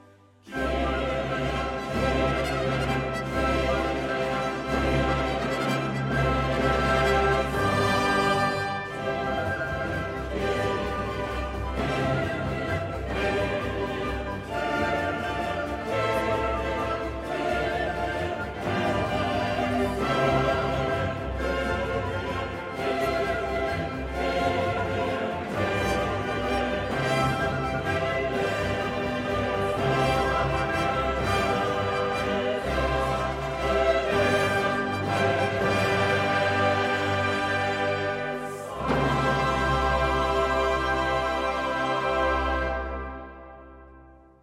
..ist ein Instrumentalensemble für Alte Musik in Koblenz.
Sie spielen auf Originalinstrumenten der jeweiligen Zeit oder auf originalgetreuen Nachbauten.